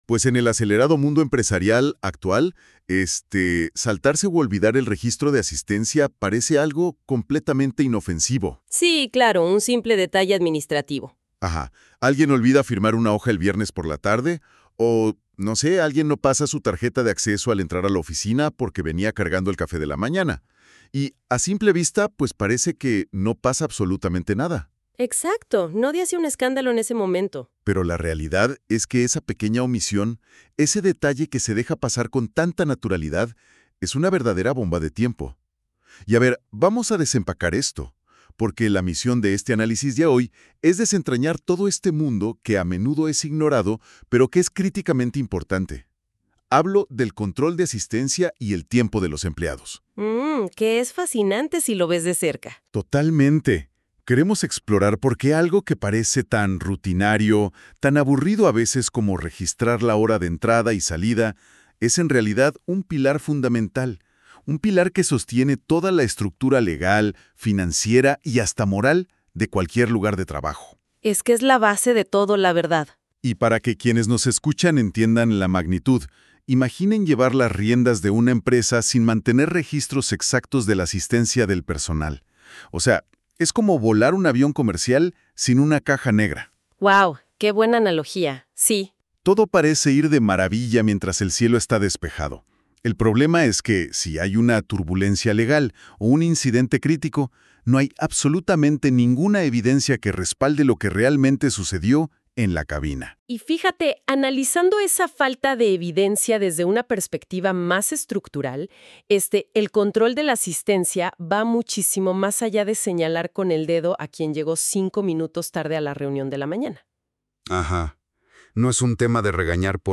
Escucha el análisis a detalle de este artículo. Dos expertos te dan consejos y te orientan en este delicado tema.